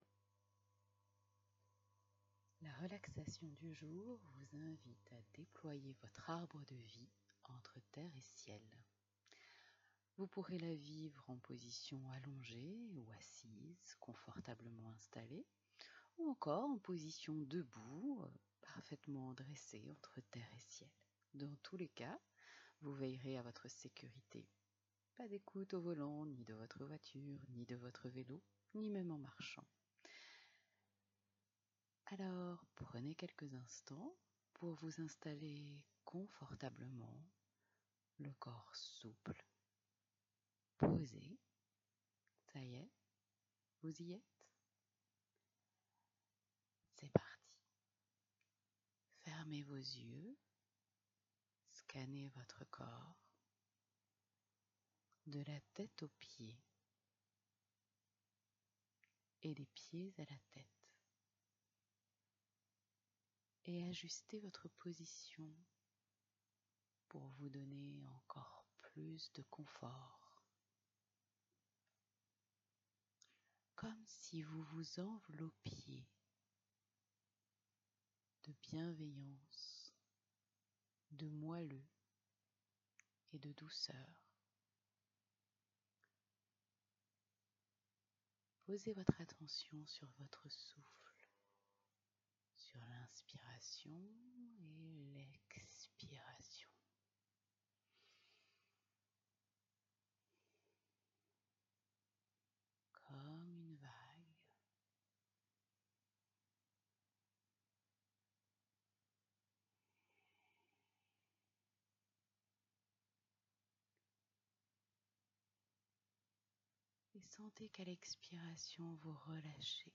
Dans cette séance, retrouvez un temps de relaxation, d’ancrage dans le corps, dans la simplicité d’être, ici, maintenant, au présent.